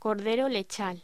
Locución: Cordero lechal
voz